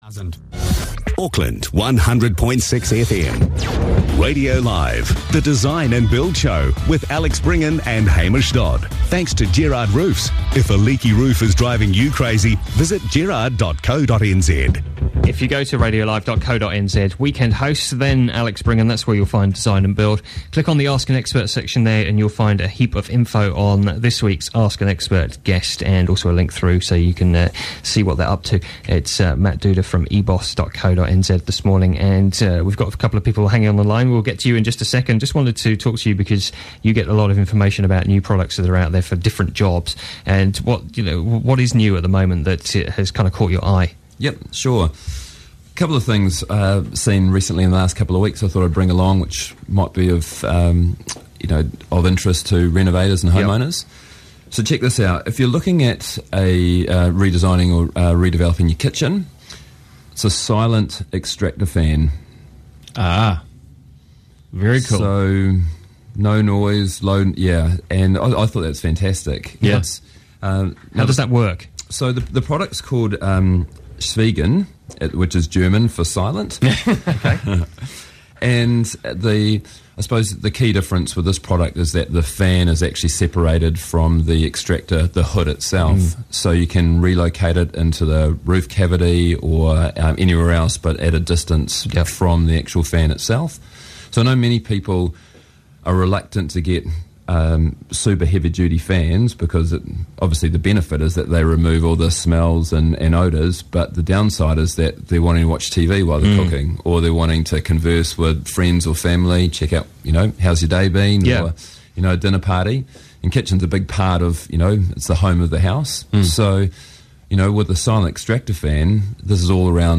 Profile on Radio Live 12/12/10